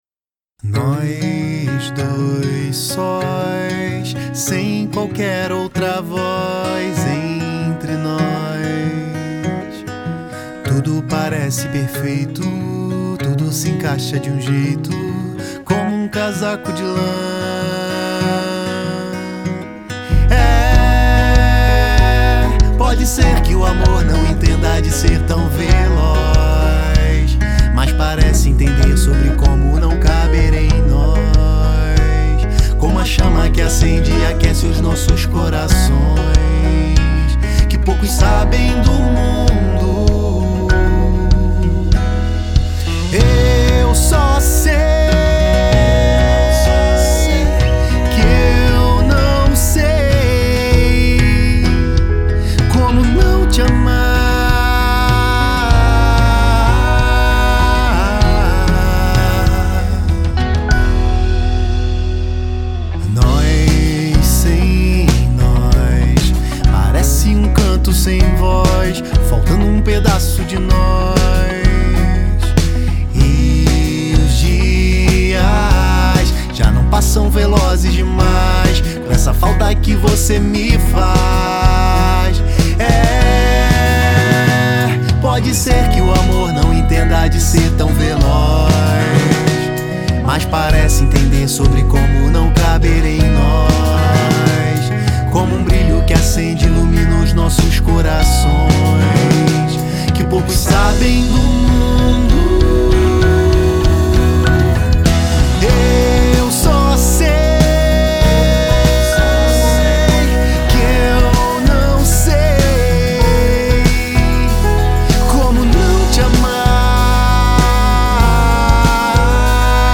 EstiloFolk